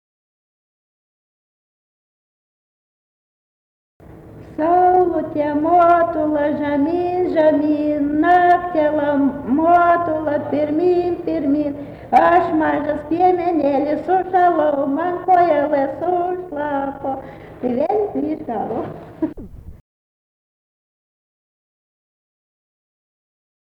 vokalinis
LMTA Mokslo centro muzikinio folkloro archyvas